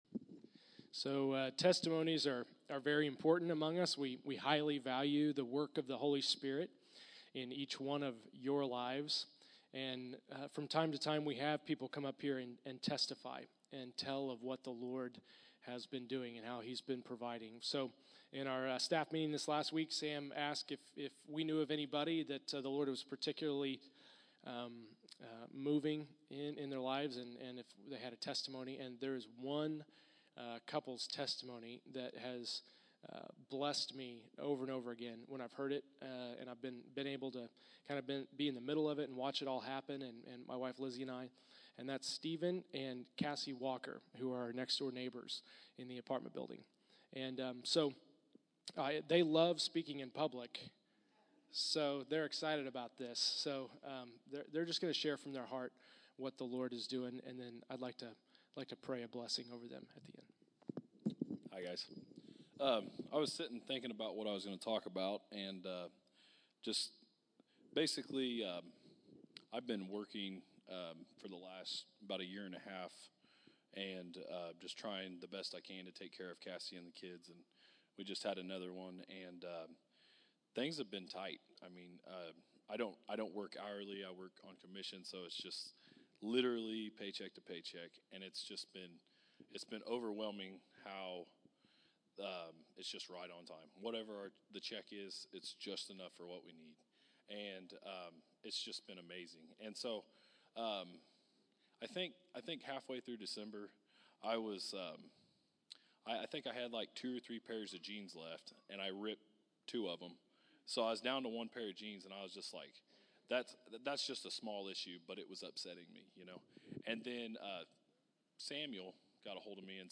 January 18, 2015      Category: Testimonies      |      Location: El Dorado